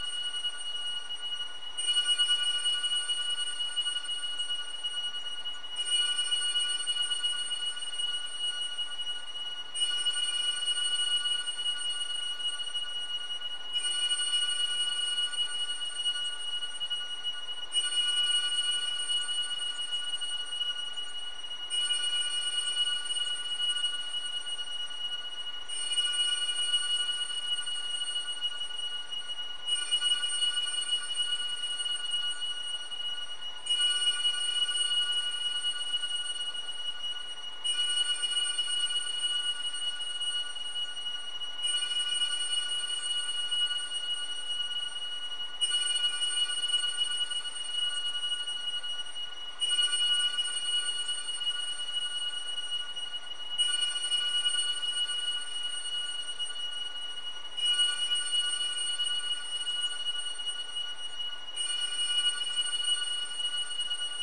口琴无人机 " harm8
描述：用口琴创作的无人机。
Tag: 风能 声学环境 处理 无人驾驶飞机 样品 迷惑 口琴